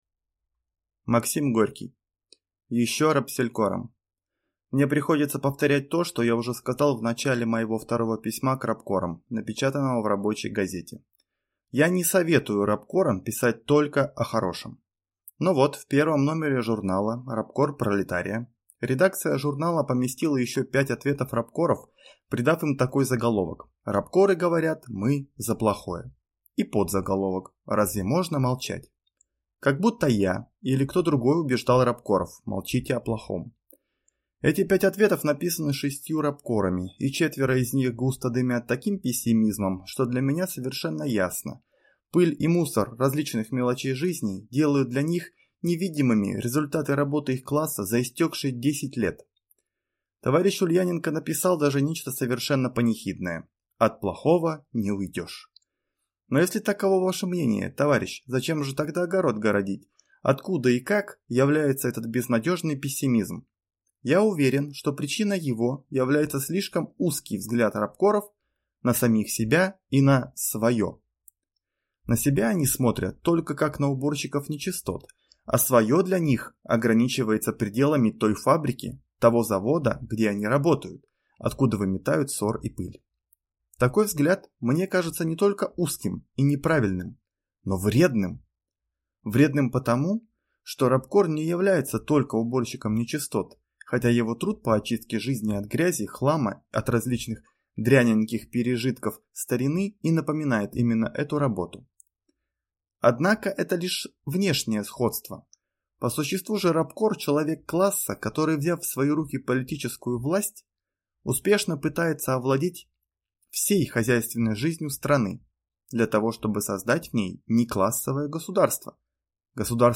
Аудиокнига Ещё рабселькорам | Библиотека аудиокниг